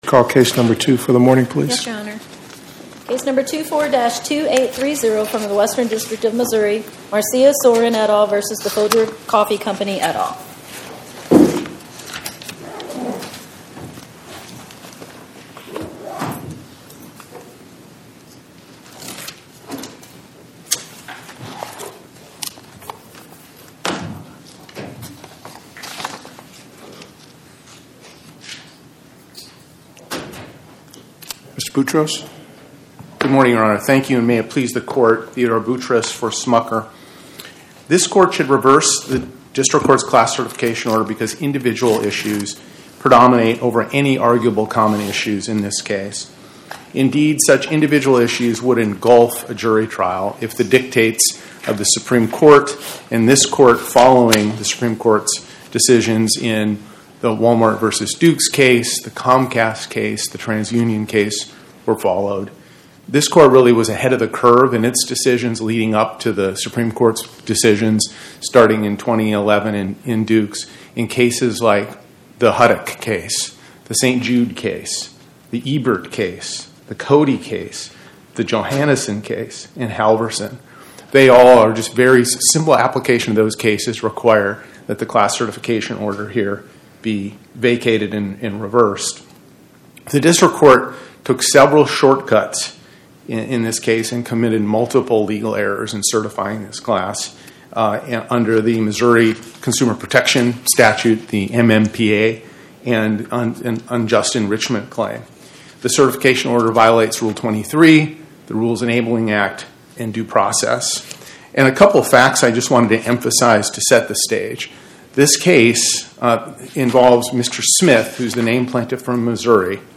Oral argument argued before the Eighth Circuit U.S. Court of Appeals on or about 09/16/2025